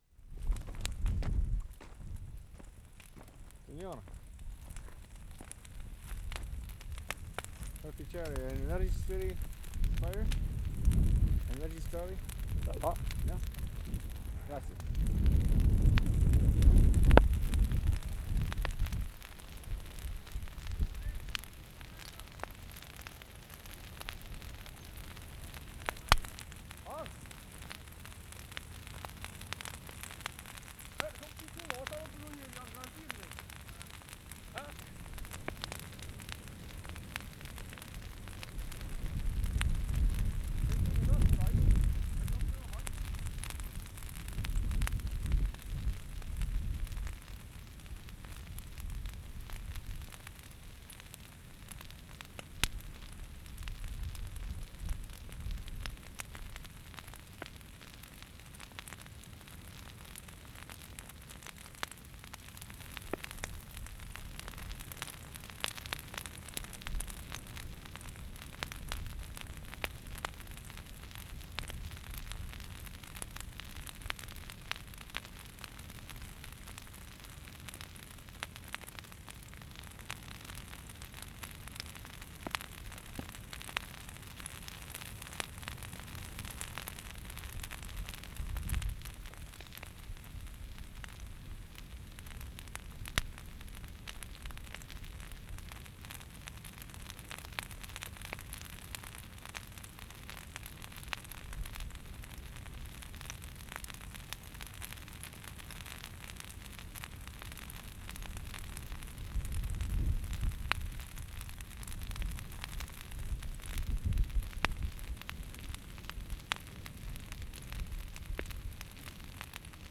Cembra, Italy March 28/75
4.  BURNING A PILE OF DEAD VINES
mark * ditto 4 (up close). [0:00]